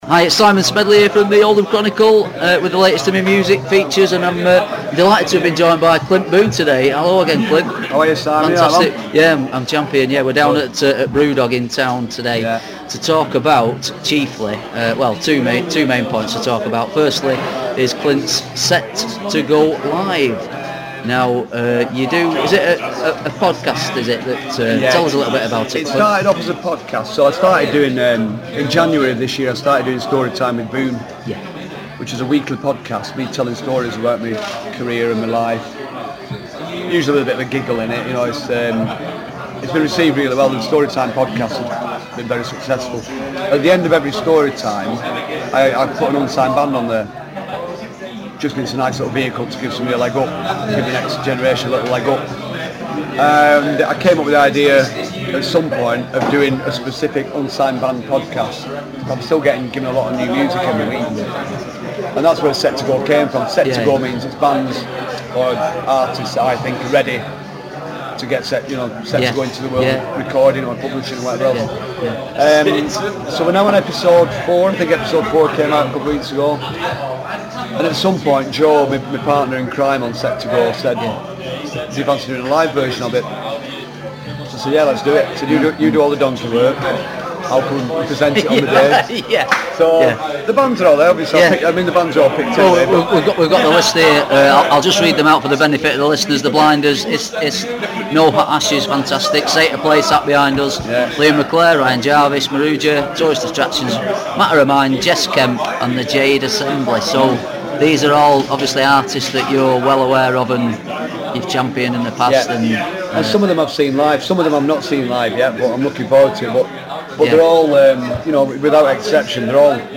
Boon also speaks enthusiastically about his forthcoming 'Set2Go Live' showcase in Manchester, when some of the region's most promising young bands play at an exc